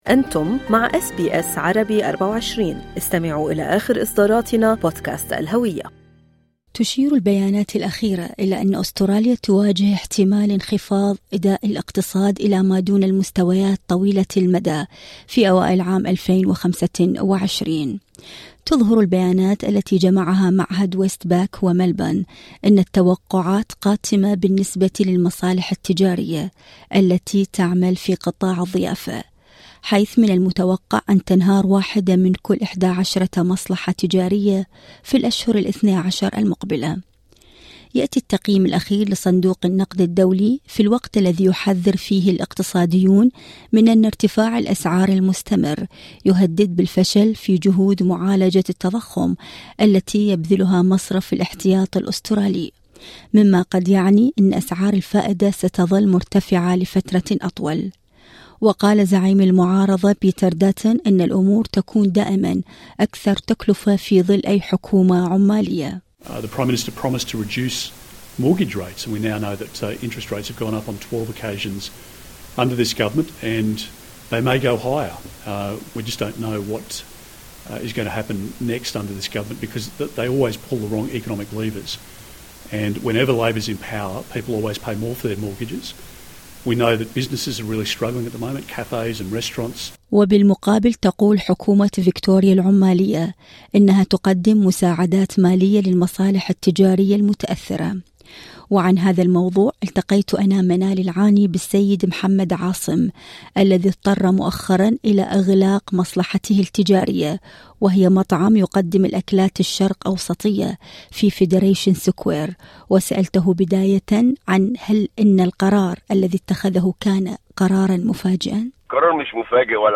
المزيد في المقابلة الصوتية اعلاه؟